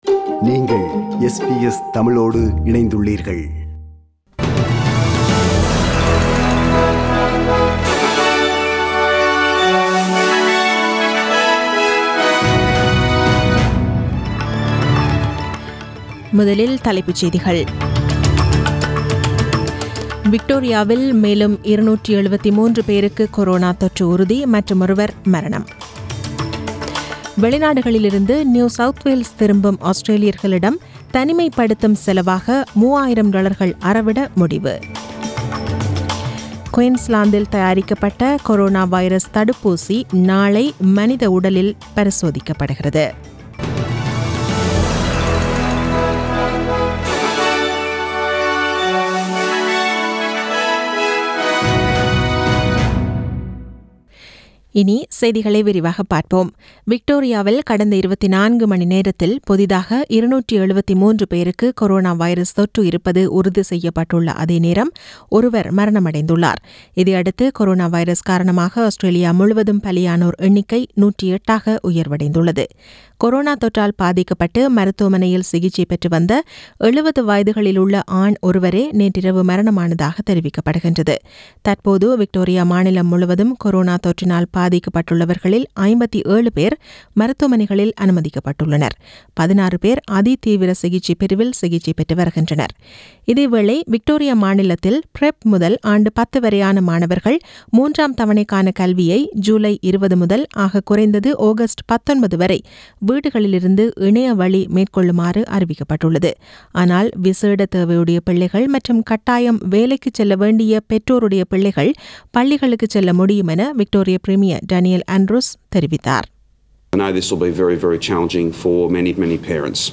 Australian news bulletin aired on Sunday 12 July 2020 at 8pm.